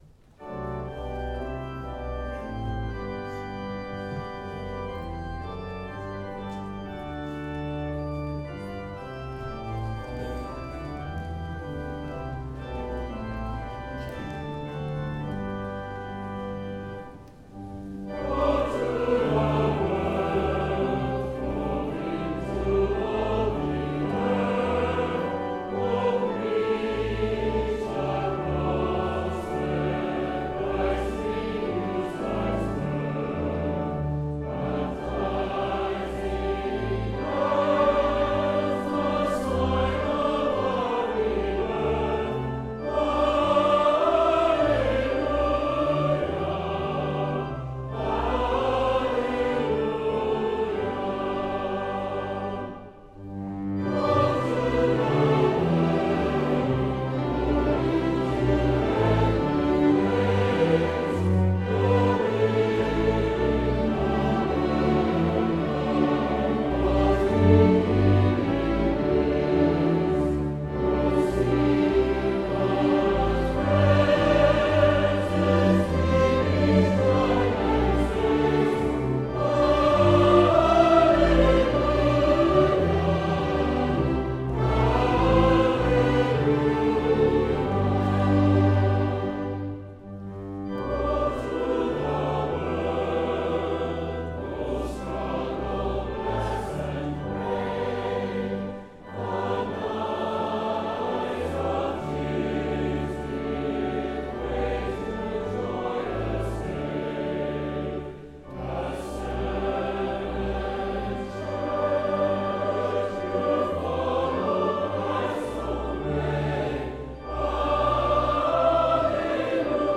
CLOSING HYMN  Go to the World                Music
Fairlawn Avenue Senior Choir and congregation